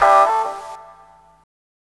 FOG FX-R.wav